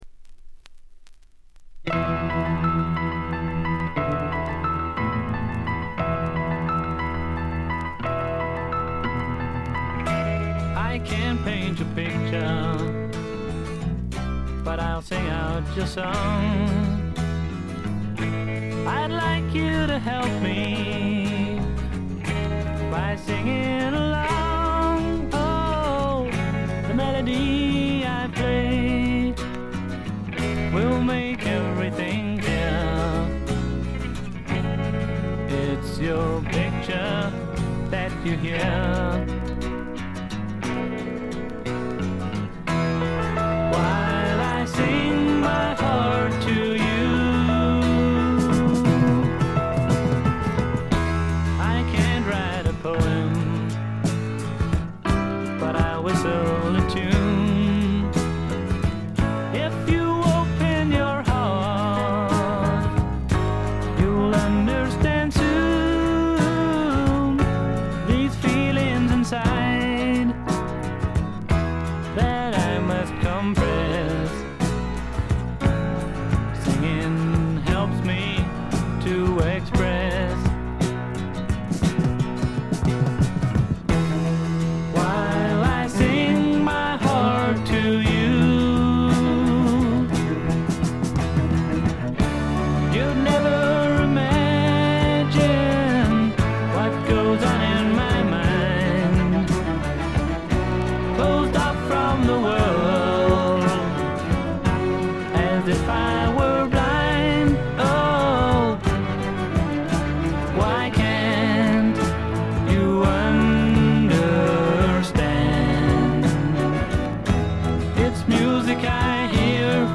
軽いチリプチ程度。
南アフリカのビートルズ風ポップ・ロック・バンド。
試聴曲は現品からの取り込み音源です。
Vocals, Acoustic Guitar
Vocals, Tambourine, Tabla